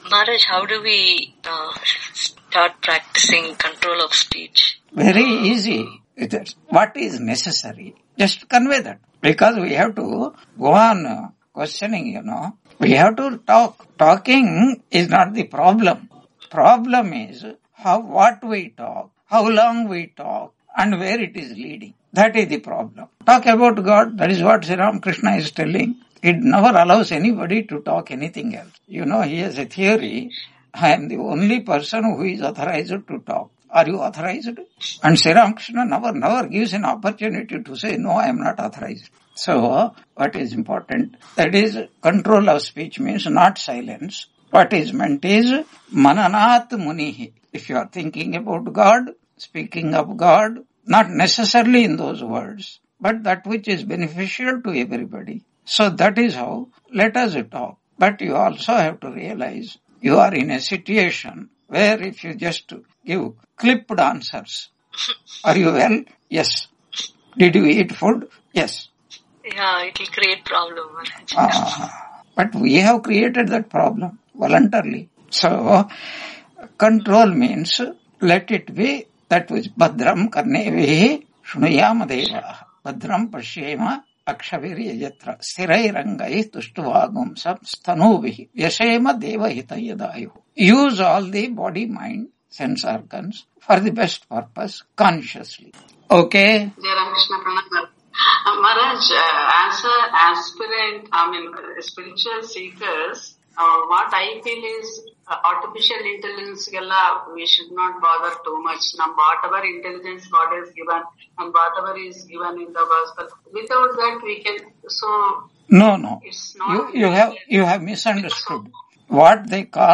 Taittiriya Upanishad Lecture 96 Ch2.9 on 18 March 2026 Q&A - Wiki Vedanta